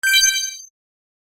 ui-confirmation-alert-a4_alt.mp3